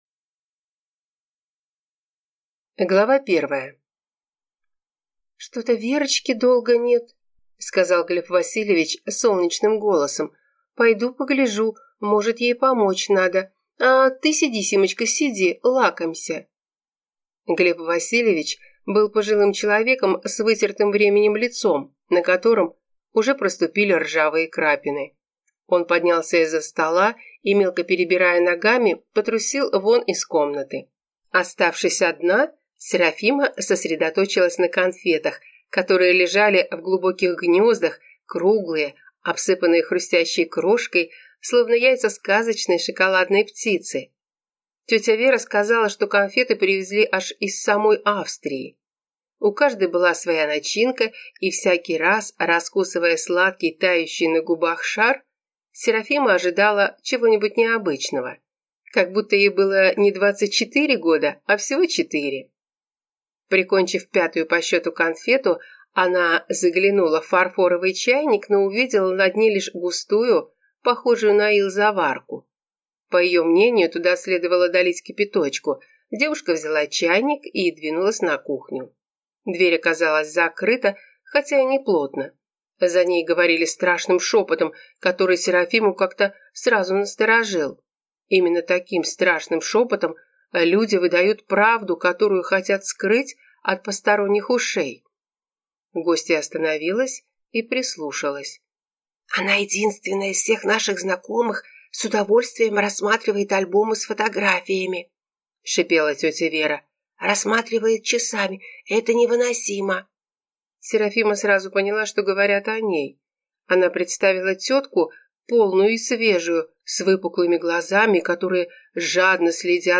Аудиокнига Уволить секретаршу!